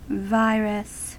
Ääntäminen
US : IPA : [ˈvaɪ.rəs]